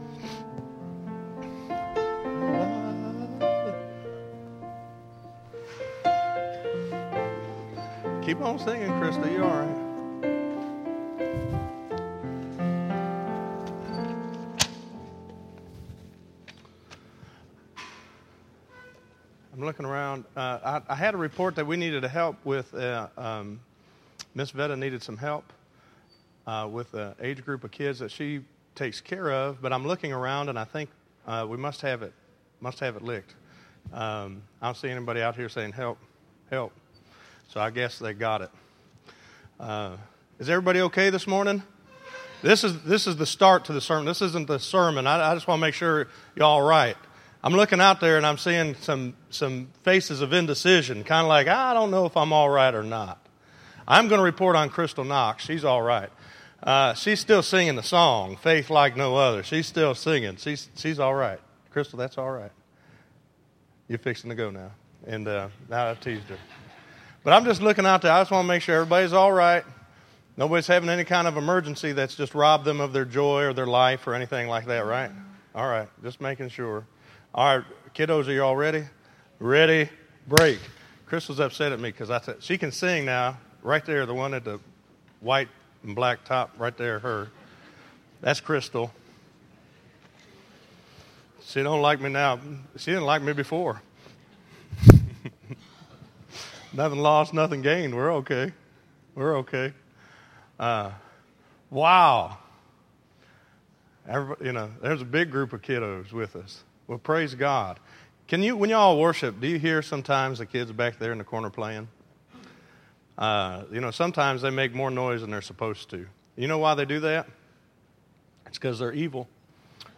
Listen to Really Knowing God - 03_09_2014_Sermon.mp3